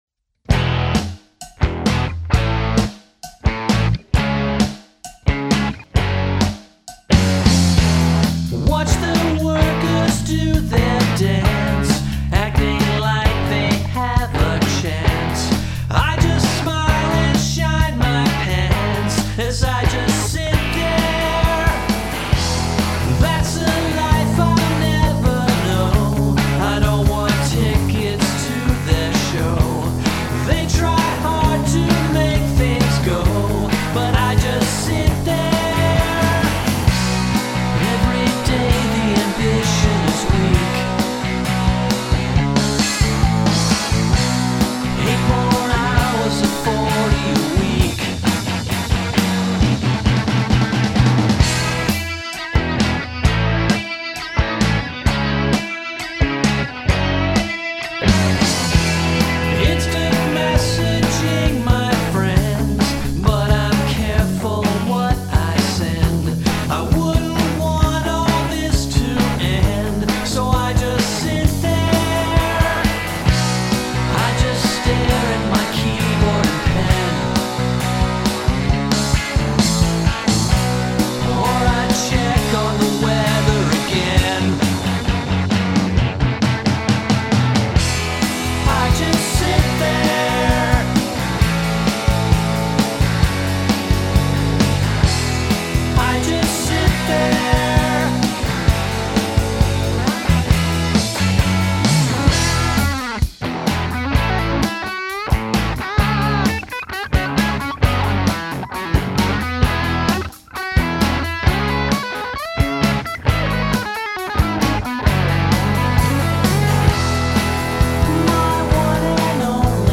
It’s tight, succinct, and to-the-point.